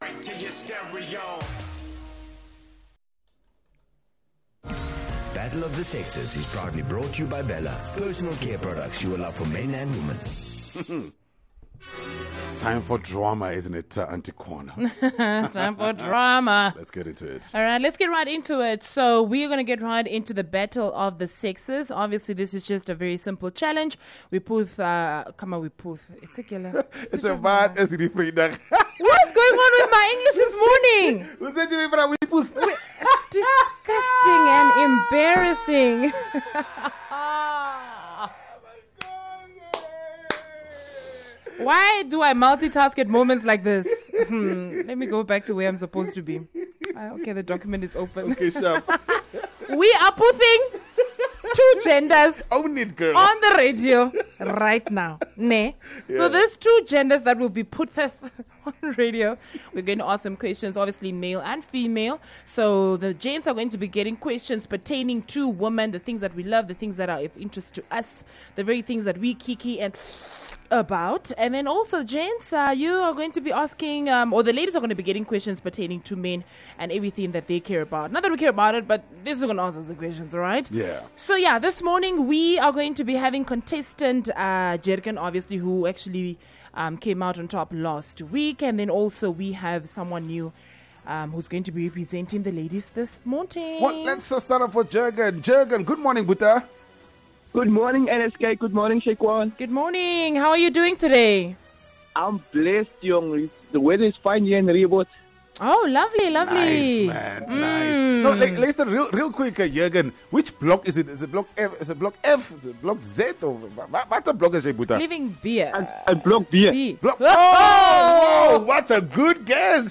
Battle of the Sexes is probably the most dramatic game show on Namibian radio. This is the bit where we have both genders on air with the aim to see which knows more about the other. So we will ask the gents questions about the ladies and ladies…we will ask questions about the gents!